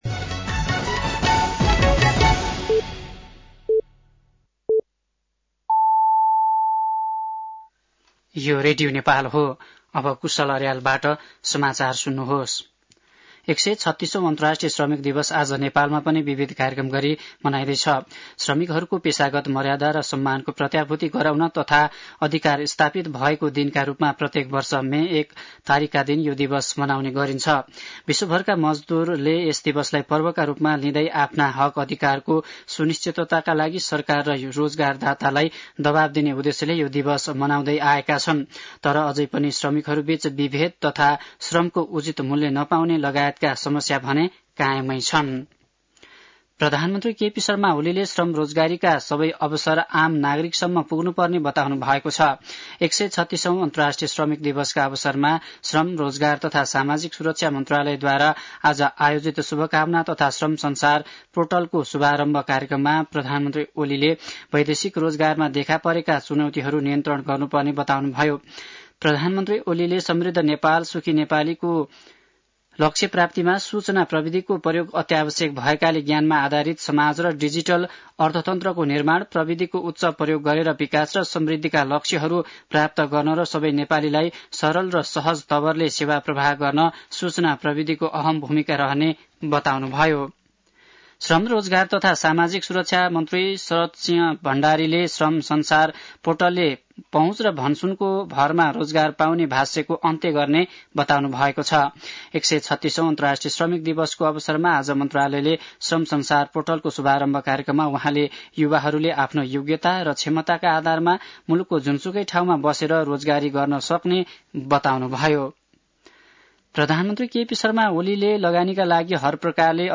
साँझ ५ बजेको नेपाली समाचार : १८ वैशाख , २०८२
5-pm-news.mp3